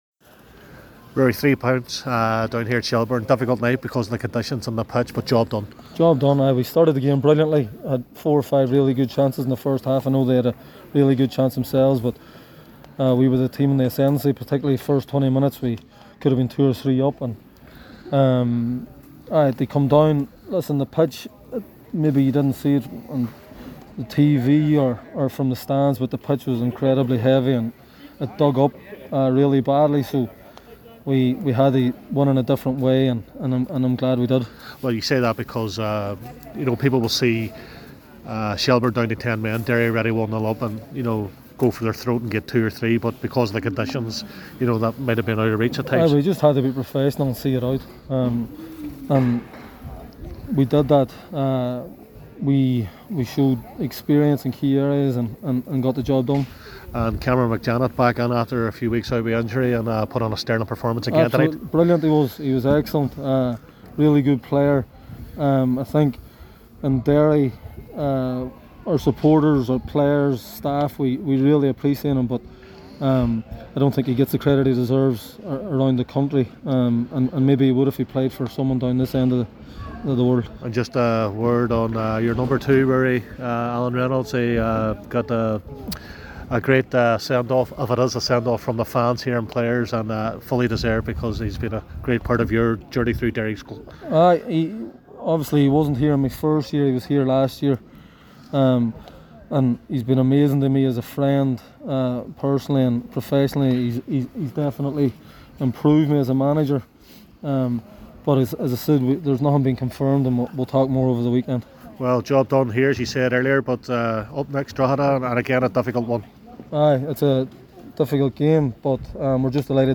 Shelbourne 0-1 Derry City, post match reaction from Tolka Park